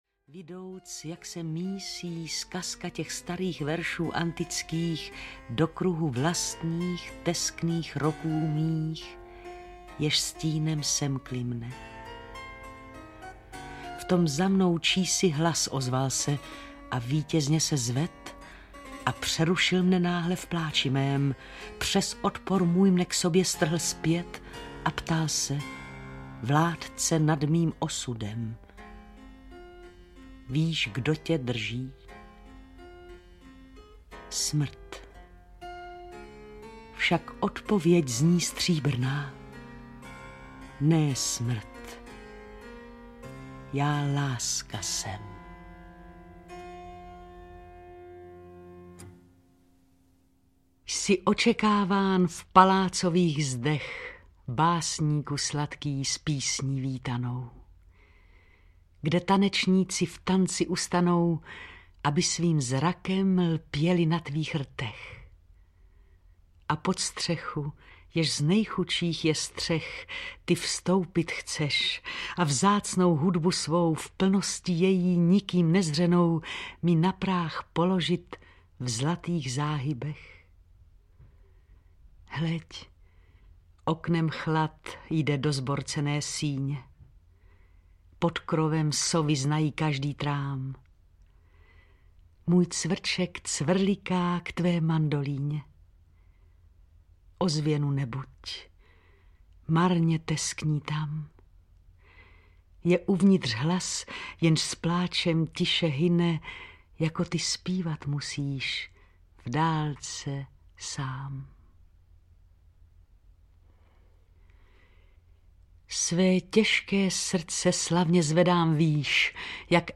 Portugalské sonety audiokniha
Ukázka z knihy
• InterpretJiřina Petrovická